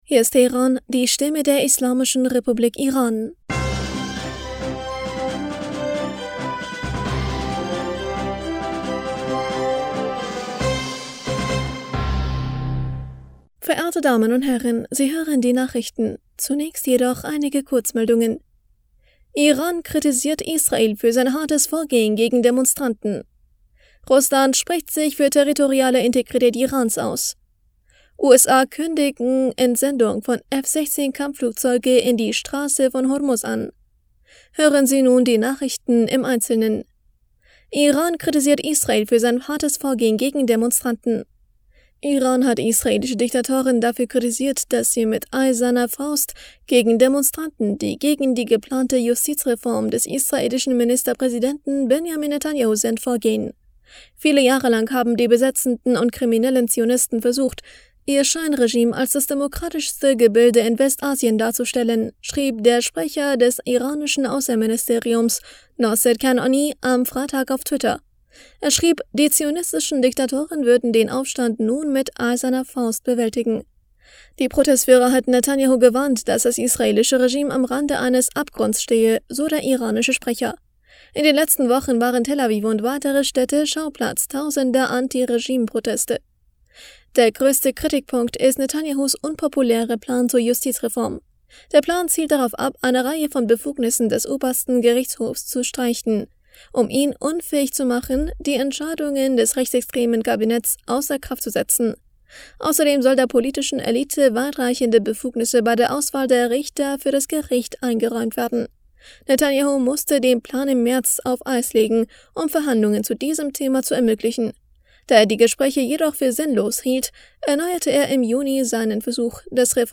Nachrichten vom 15. Juli 2023